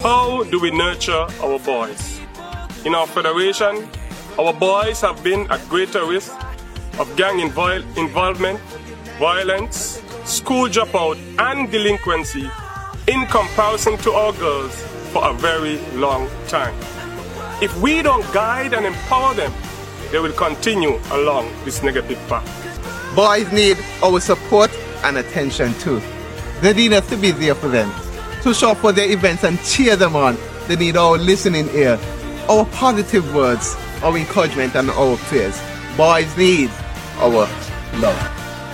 Men of the Department of Gender Affairs – St. Kitts shares this message to the nation: